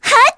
Ophelia-Vox_Attack3_kr.wav